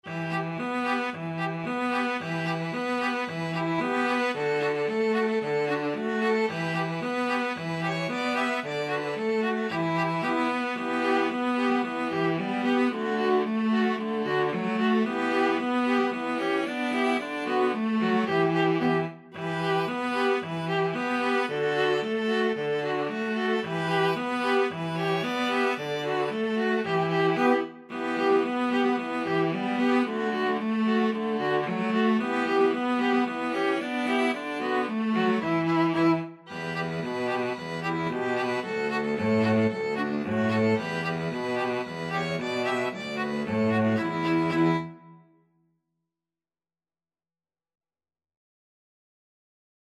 ViolinViolaCello
E minor (Sounding Pitch) (View more E minor Music for String trio )
Allegro moderato = c. 112 (View more music marked Allegro)
2/4 (View more 2/4 Music)
String trio  (View more Easy String trio Music)
Traditional (View more Traditional String trio Music)